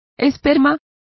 Complete with pronunciation of the translation of sperm.